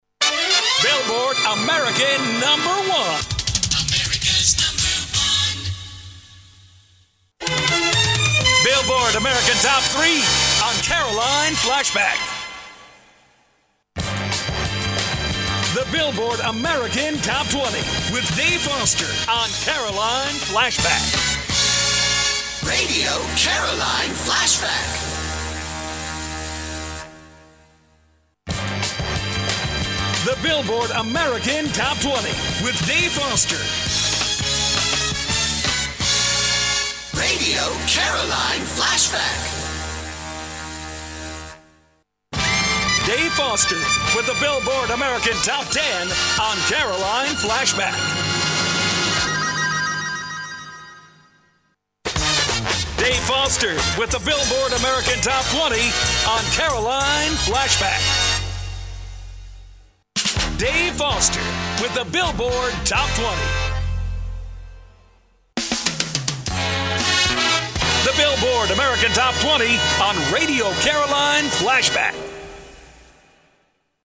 Veteran Midwest American journalist, with cartoons and beyond!